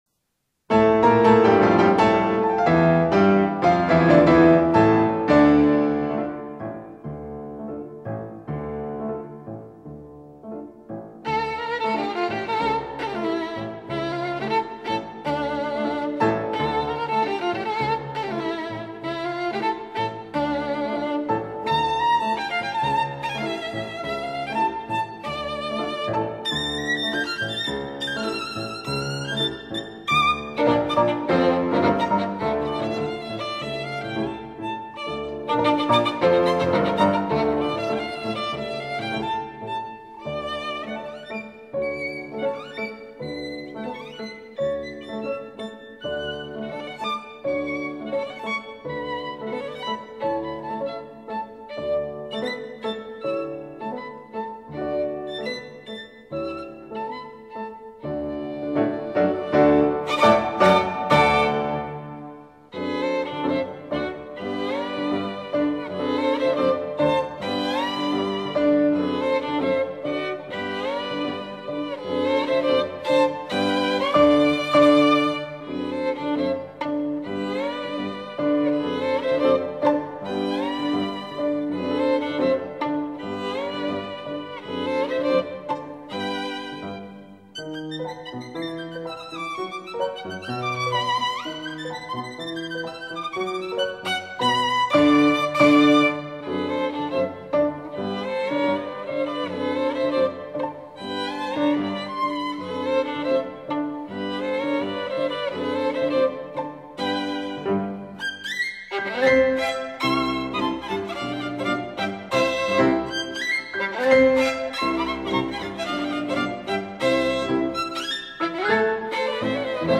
موسیقی کلاسیک شاهکار به نام " Habanera " از نوازنده ویولون اسپانیایی " Pablo De Sarasate "
sarasate-habanera_itzhak_perlman.mp3